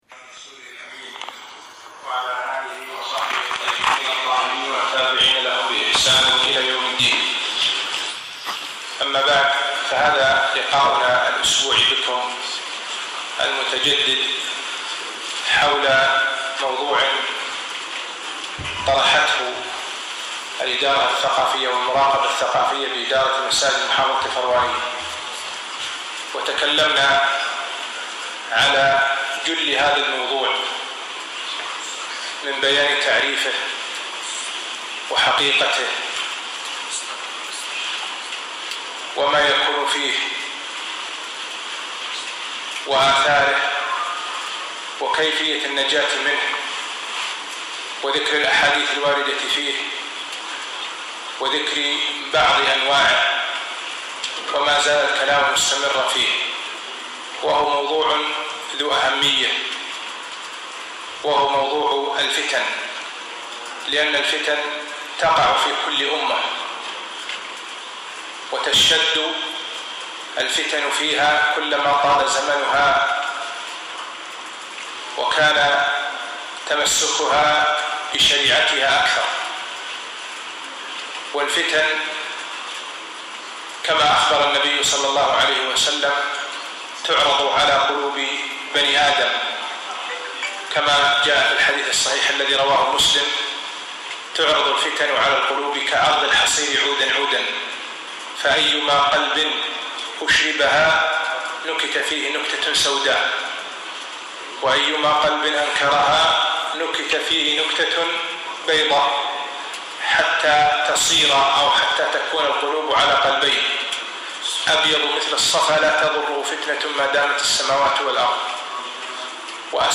المحاضرة السابعة - فتنة الإختلاف والإفتراق